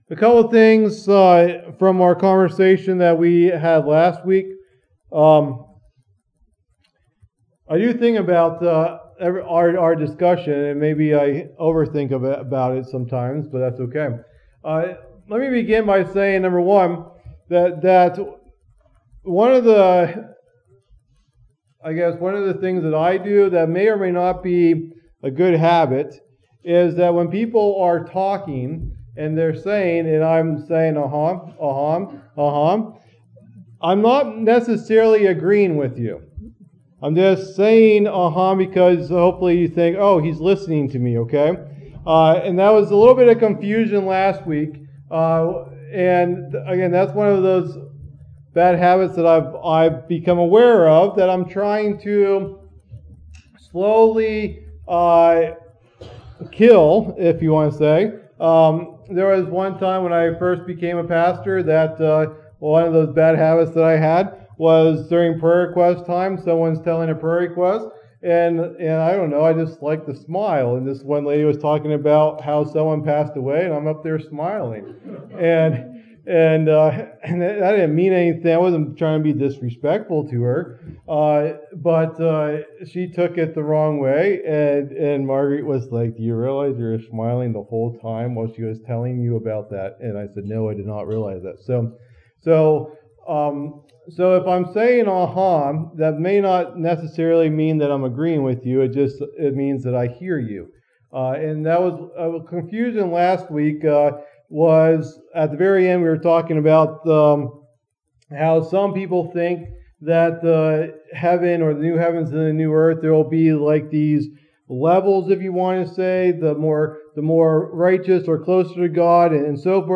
Message #54 of the "Book of Revelation" teaching series